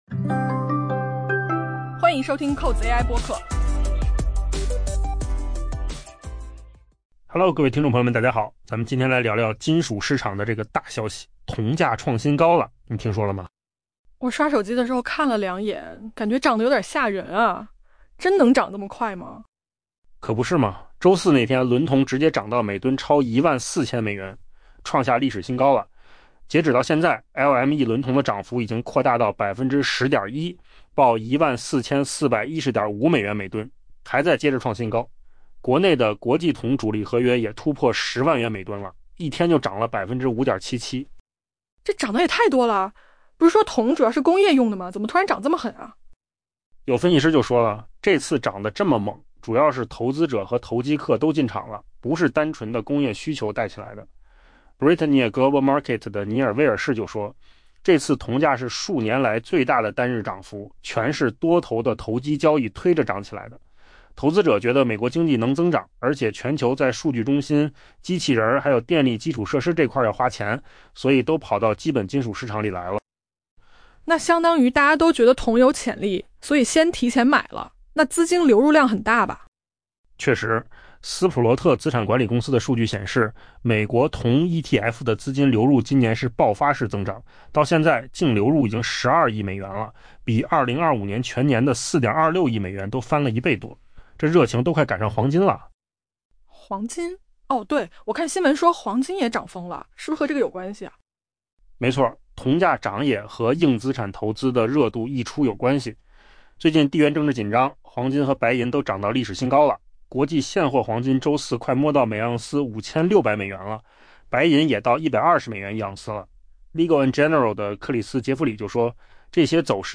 AI播客：换个方式听新闻 下载mp3 音频由扣子空间生成 受强劲需求预期、美元走弱及地缘政治担忧支撑，投机客继续大举买入，推动铜价周四创下每吨超14000美元的历史新高。